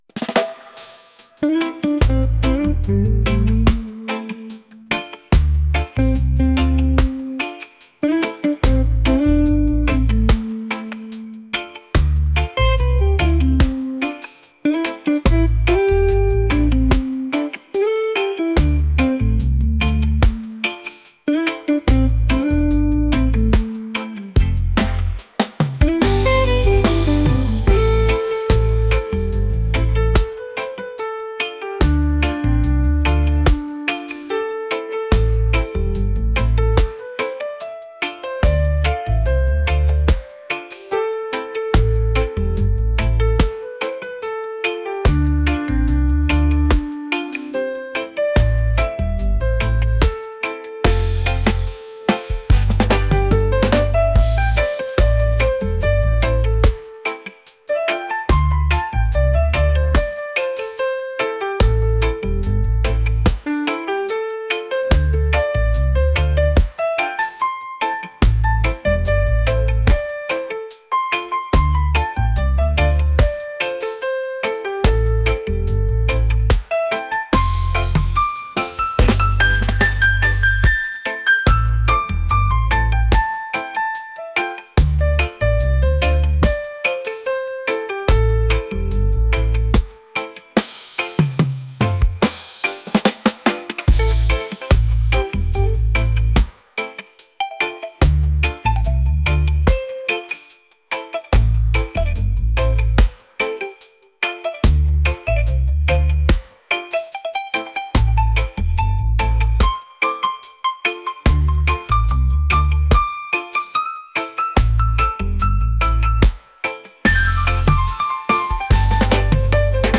reggae | smooth